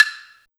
176TTCLAVE-R.wav